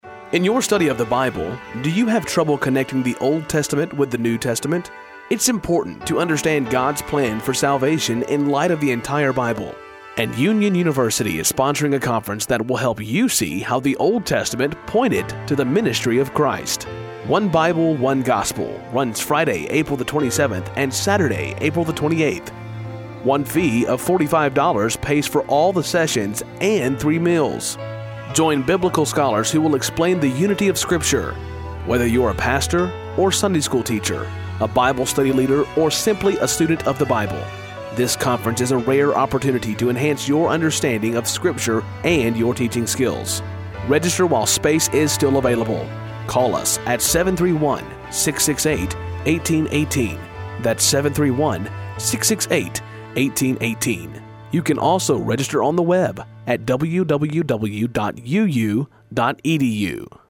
RadioSpot60.mp3